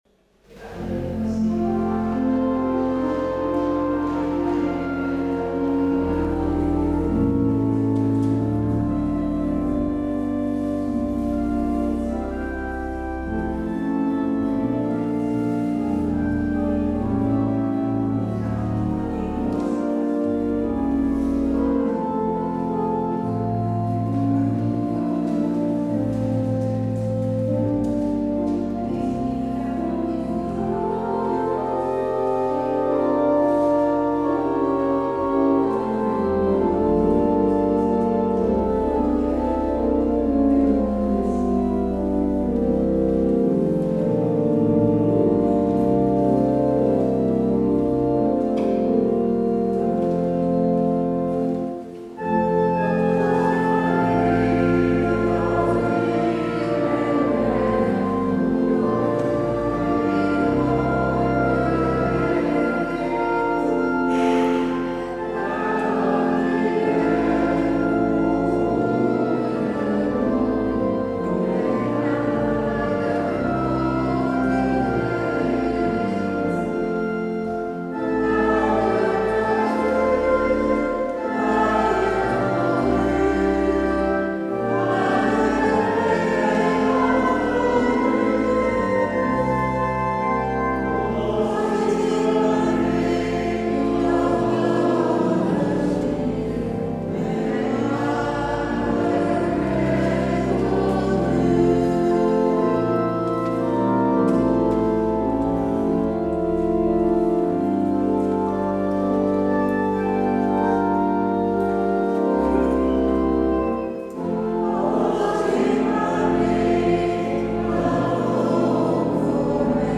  Luister deze kerkdienst hier terug
Het openingslied is: Psalm 25: vers 4 en 6.